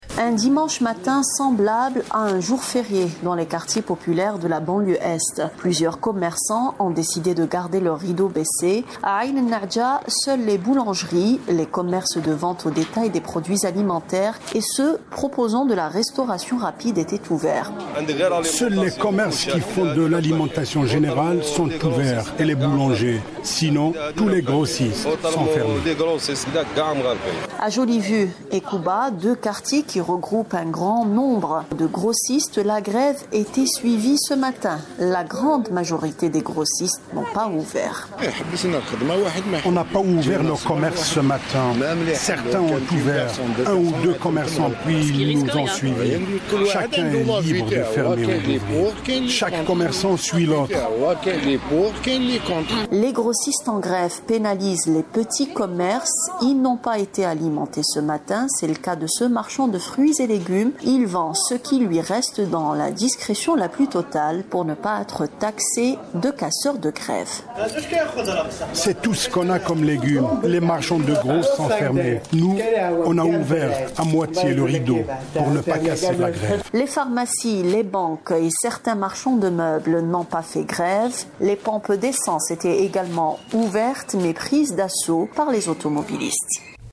Gréve générale des commerçants à Alger - Reportage